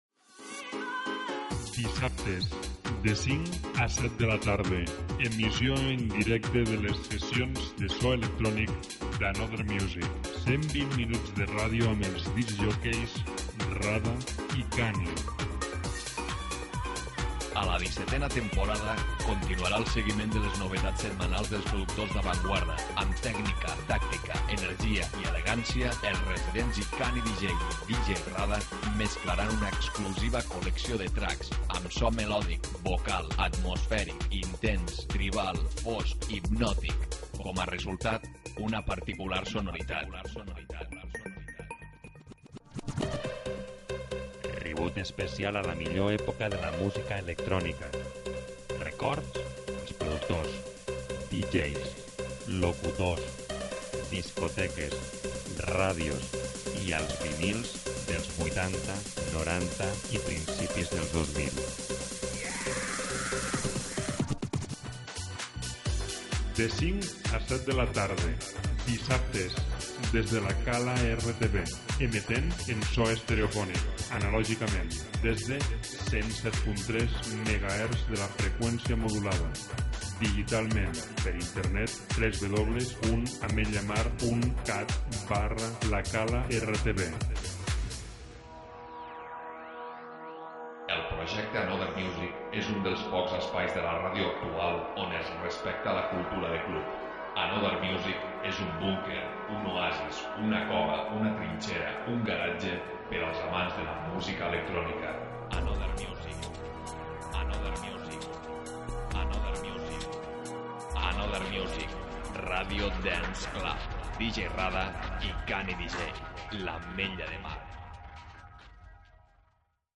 en directe el dissabte 29/04/17.